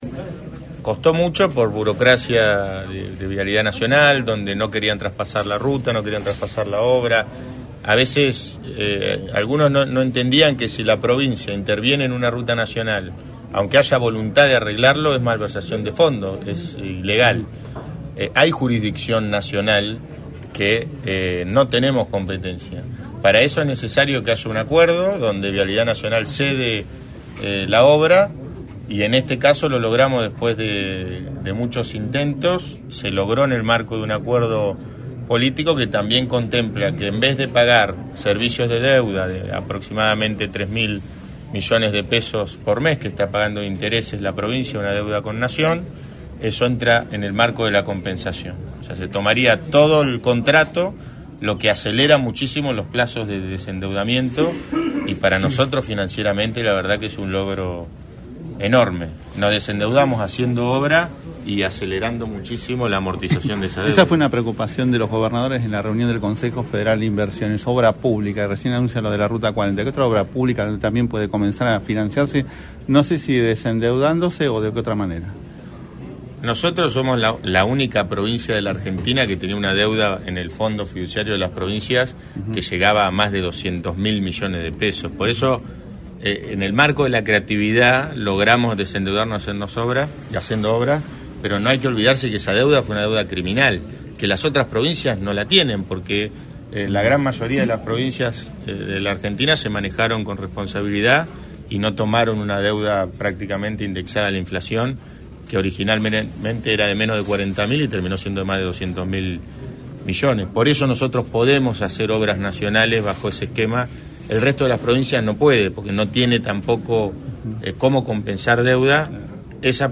En la conferencia de prensa de hoy el gobernador habló de todo